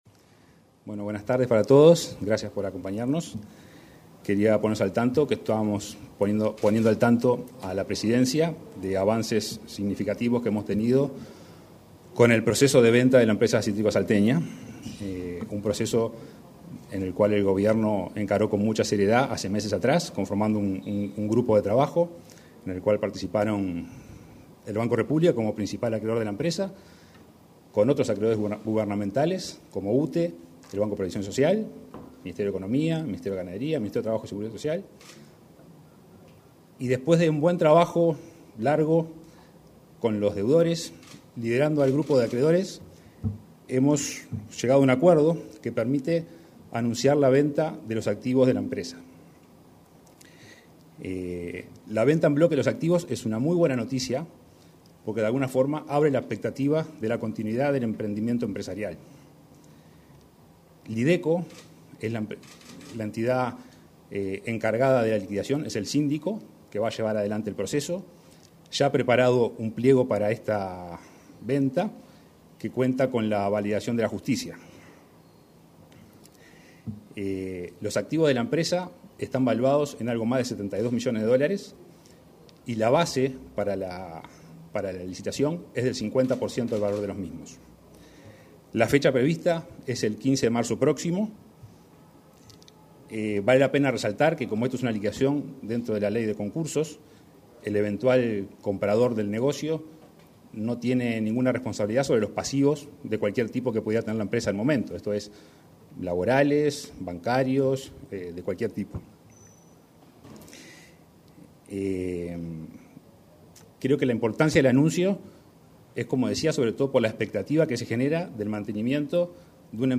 Conferencia del presidente del BROU, Salvador Ferrer
Este lunes 20 de diciembre realizó una conferencia de prensa el economista Salvador Ferrer.